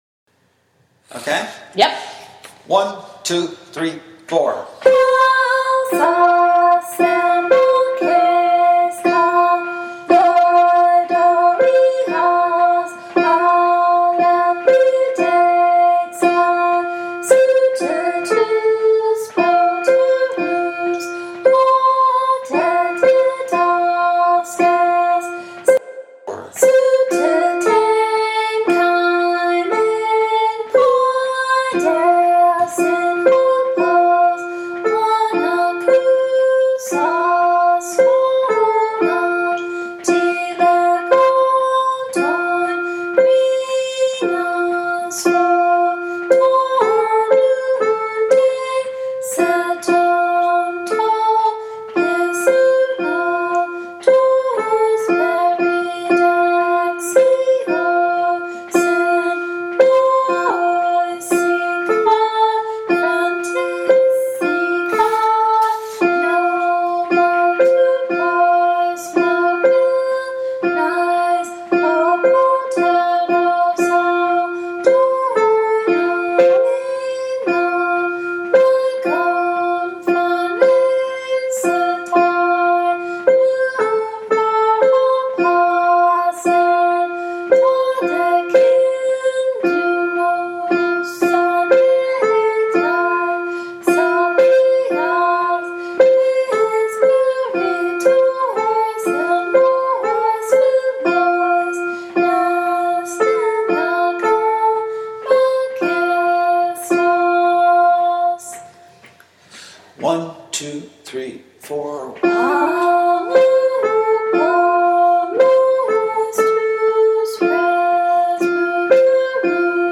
The music for Chorus 1
CloudsChorus1.mp3